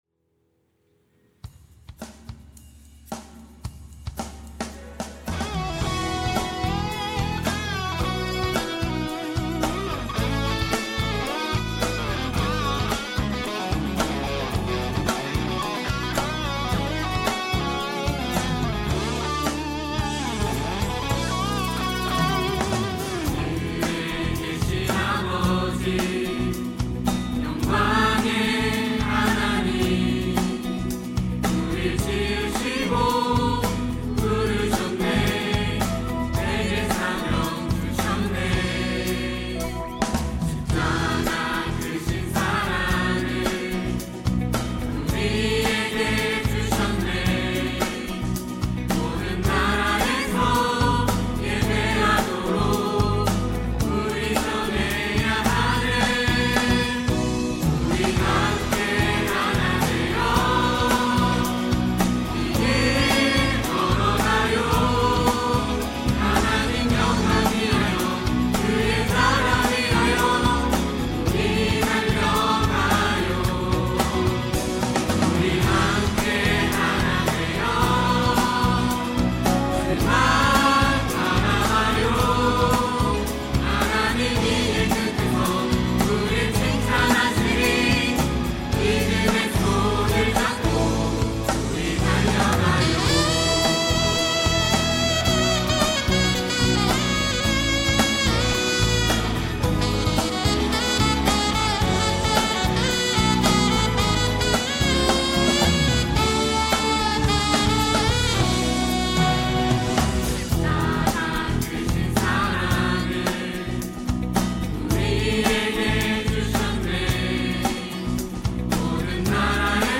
특송과 특주 - 우리 함께 걸어가요
청년부 3팀